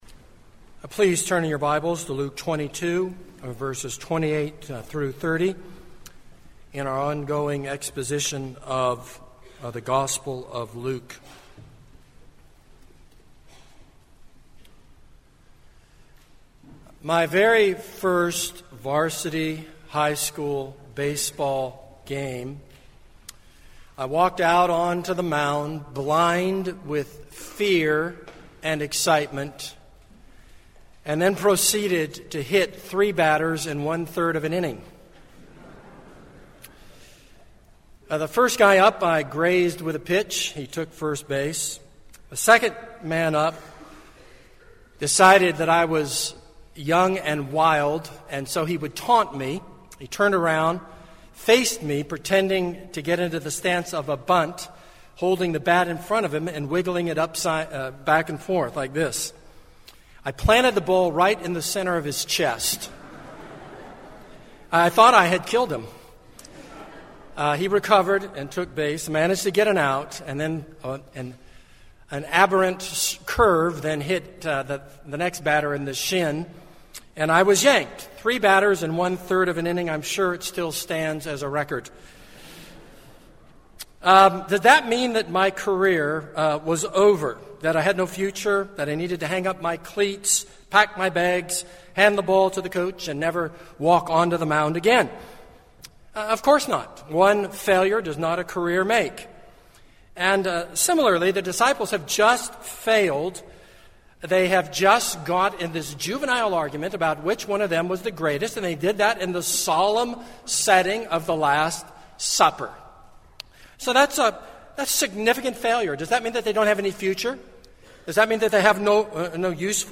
This is a sermon on Luke 22:28-30.